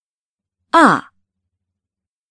Señala el tono que oigas